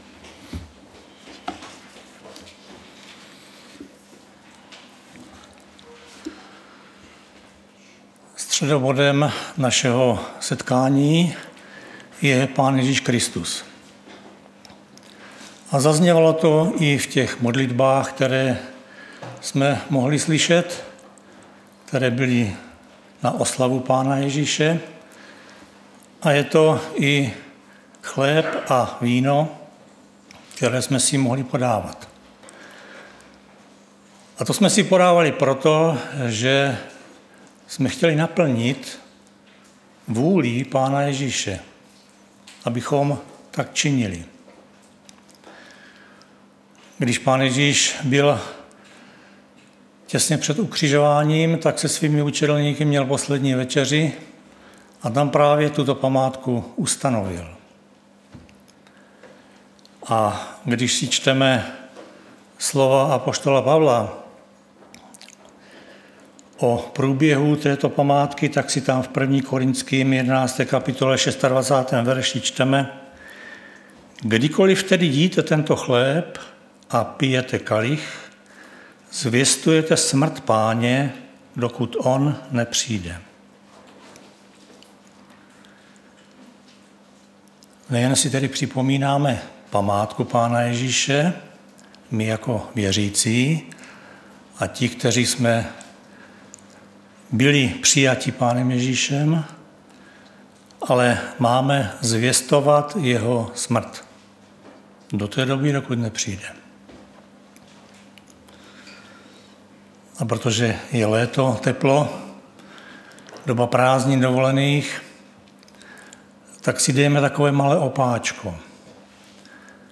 Kázání
Vzpomínkový záznam staršího kázání